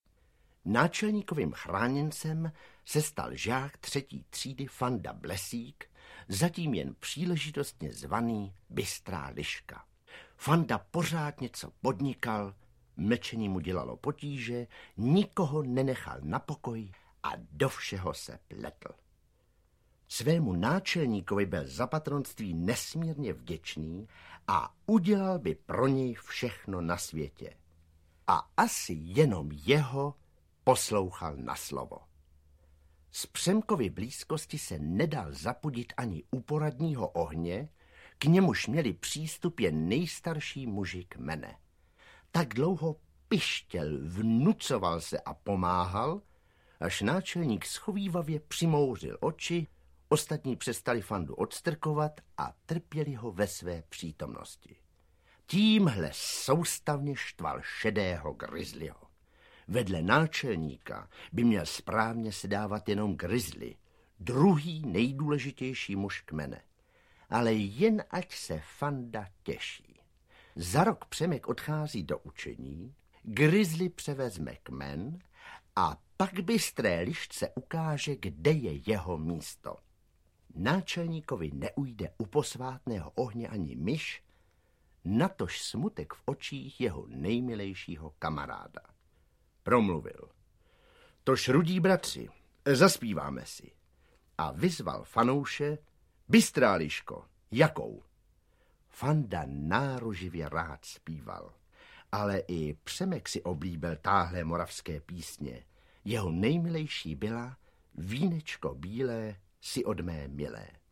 Indiáni z Větrova audiokniha
Pro Supraphon ji s osobitým půvabem převyprávěl Petr Nárožný
Ukázka z knihy
indiani-z-vetrova-audiokniha